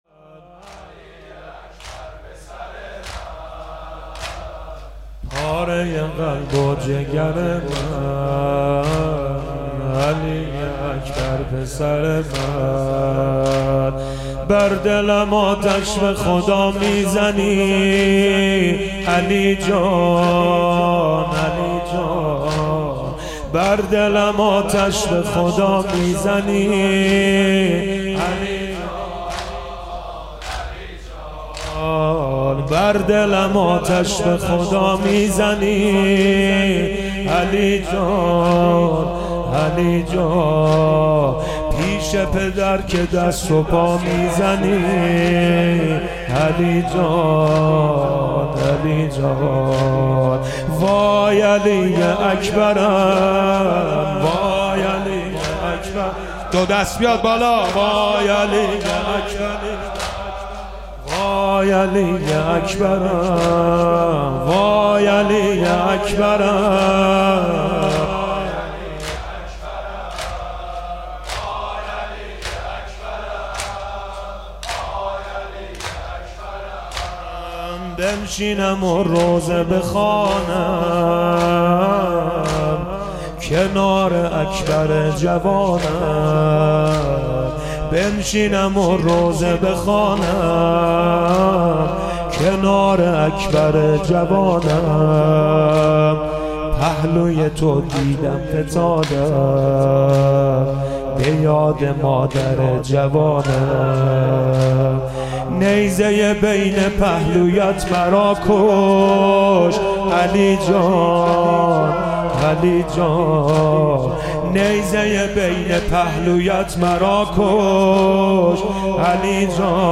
مداحی جدید
مراسم هفتگی مجمع حیدریون زنجان